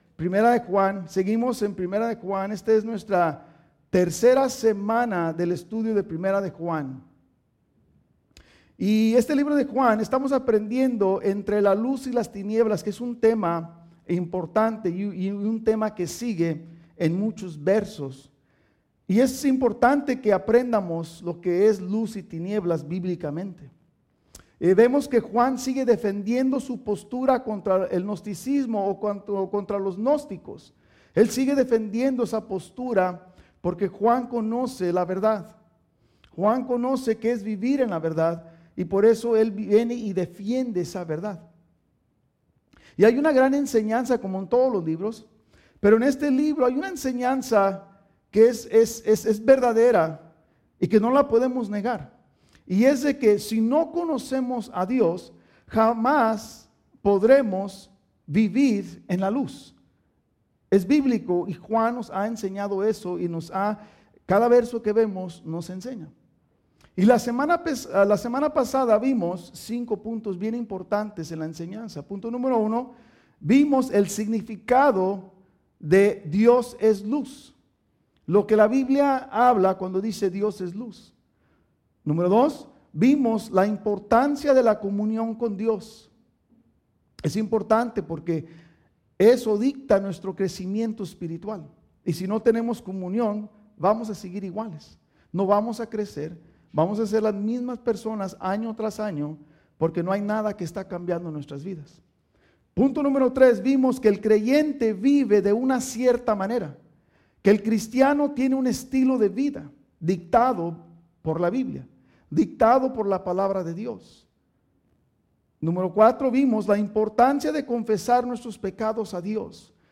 Estudio Biblico | Iglesia Vida Hammond